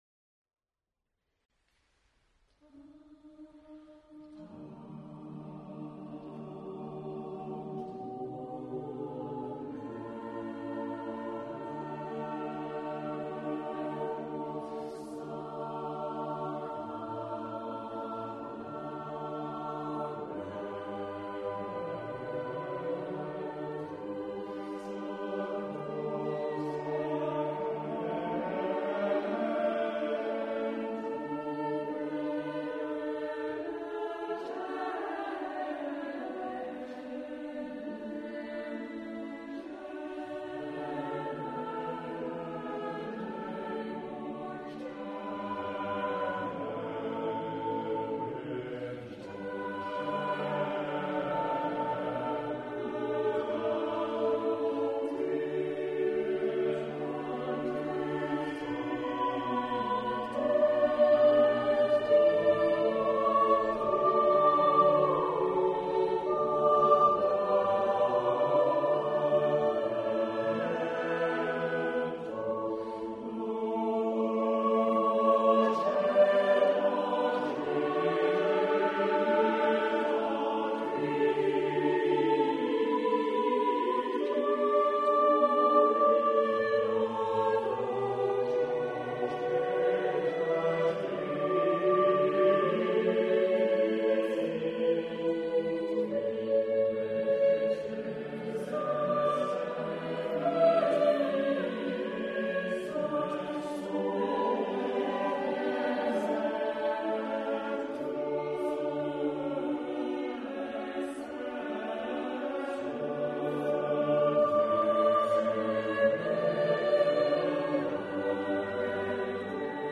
Cherwell Singers Concert March 2007